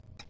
ClockTick.mp3